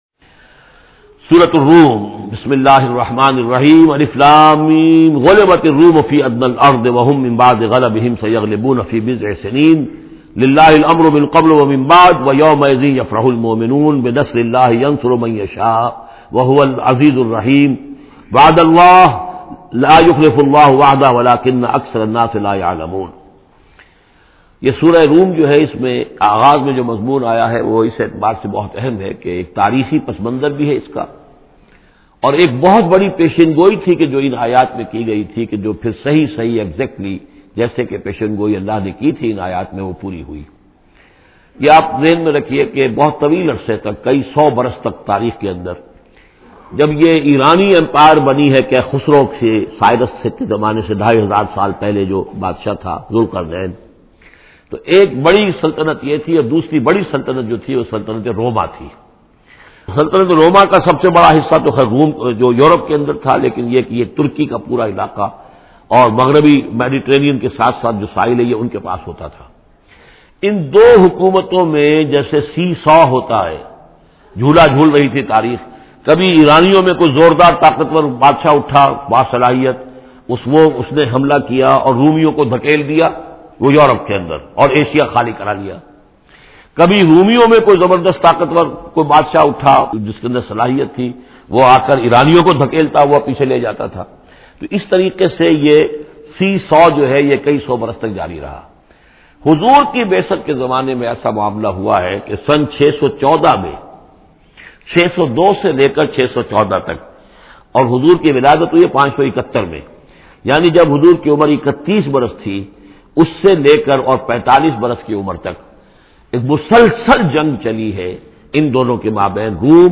Listen urdu tafseer of Surah Ar Rum in the voice of Dr Israr Ahmed.